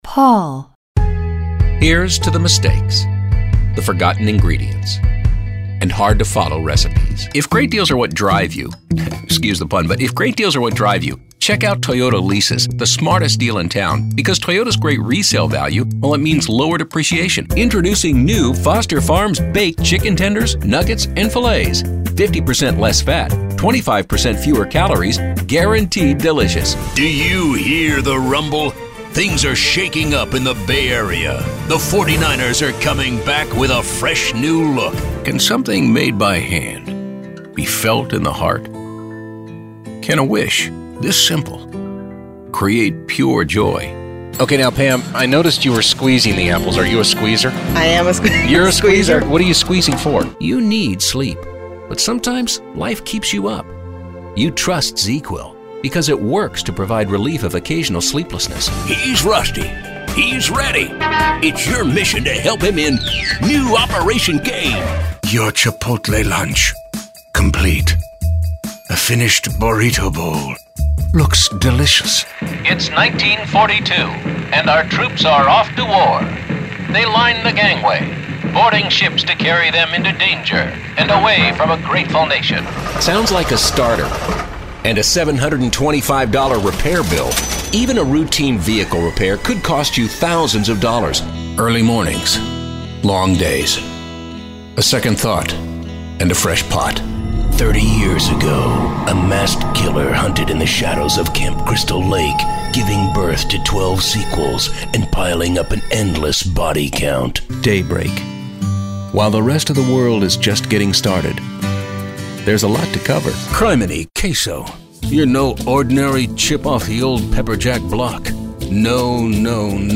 A great actor who can portray any character you need and the consummate spokesperson with years of experience.
Showcase Demo